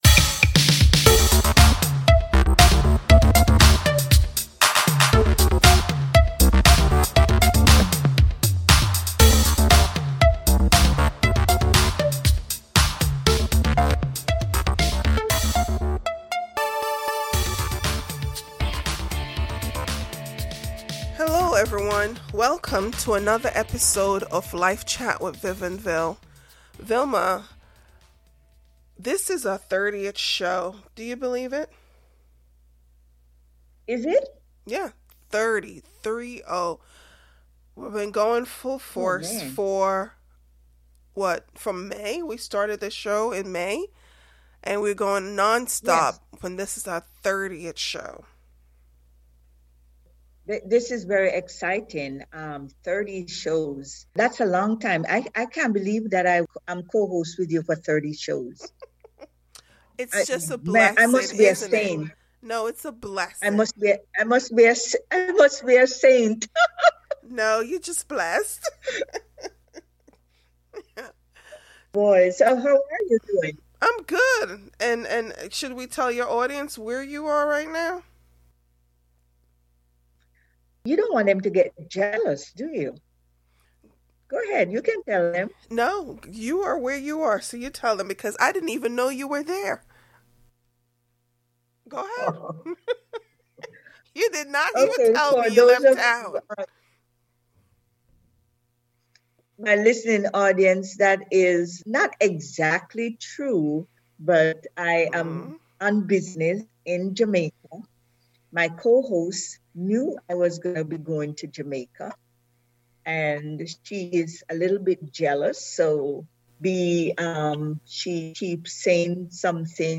Guest and hosts discuss things one could do to make retirement more exciting. Also hosts talked about the journey to that 30th episode.